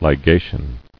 [li·ga·tion]